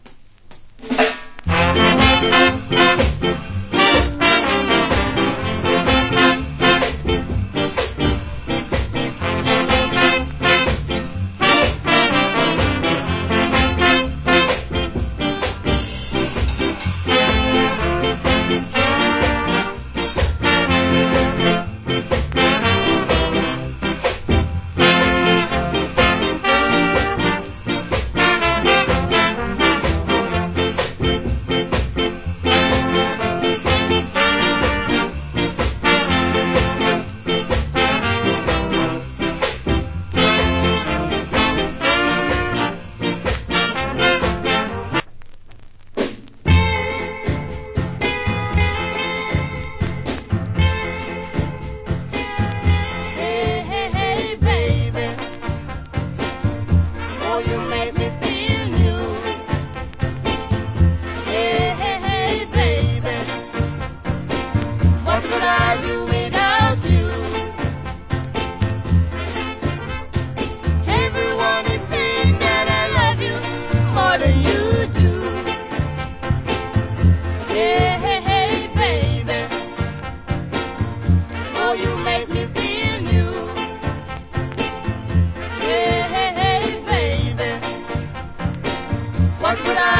Cool Ska Inst.